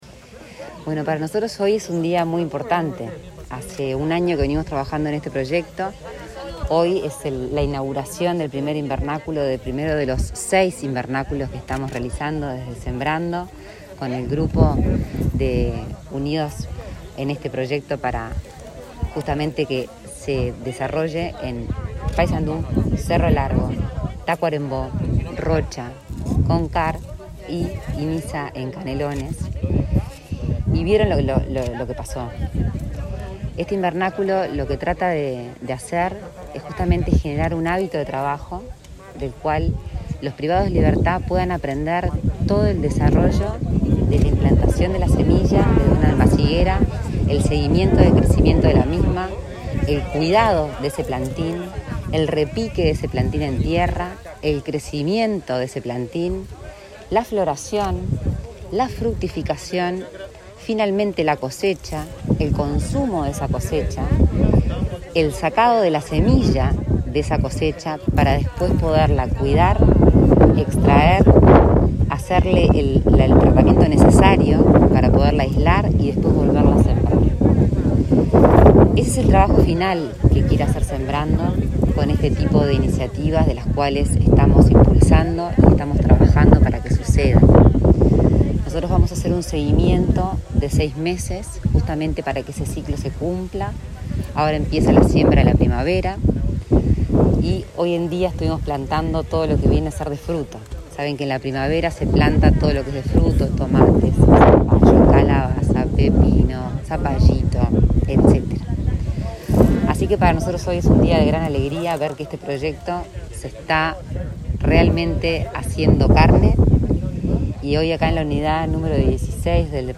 Declaraciones a la prensa de Lorena Ponce de León
Declaraciones a la prensa de Lorena Ponce de León 19/08/2021 Compartir Facebook X Copiar enlace WhatsApp LinkedIn En el marco de la inauguración de una huerta del programa Sembrando, en la unidad carcelaria n.° 16 de Paysandú, la impulsora de la iniciativa, Lorena Ponce de León, dialogó con la prensa.